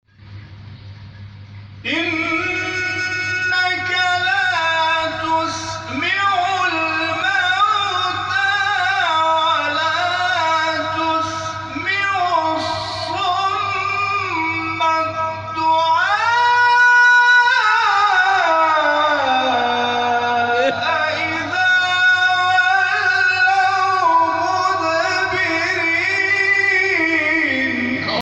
حساسیت بر آموزش ترکیبات لحنی در جلسه هفتگی بیت‌القرآن